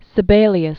(sĭ-bālē-əs, -bālyəs), Jean 1865-1957.